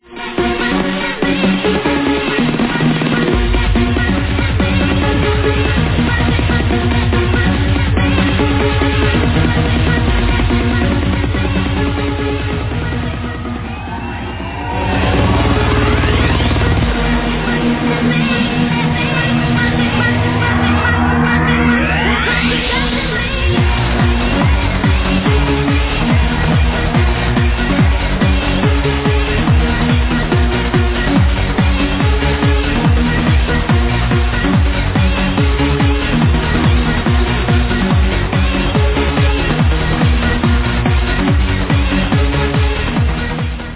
Dunno trance track from 2003 - please help !!
dont know what it is but sounds like a major tune
Typical dutch clubtrance